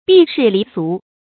避世離俗 注音： ㄅㄧˋ ㄕㄧˋ ㄌㄧˊ ㄙㄨˊ 讀音讀法： 意思解釋： 逃避濁世，超脫凡俗 出處典故： 東漢 王充《論衡 定賢》：「以清節自守，不降志辱身為賢乎？